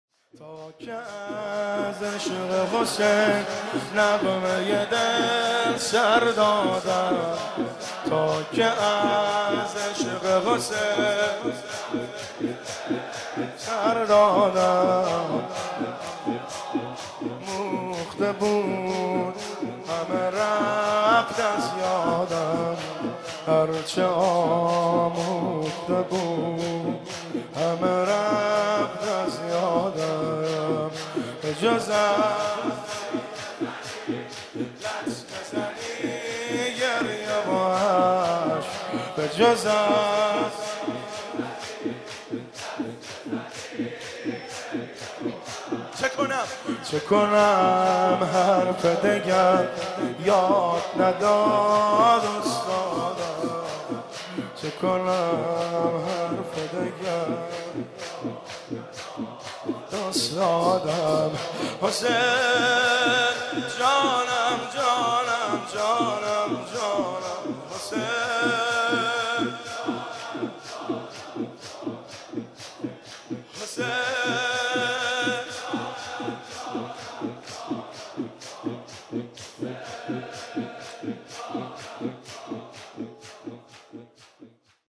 مجموعه نوحه های جلسه هفتگی
با نوای گرم
شور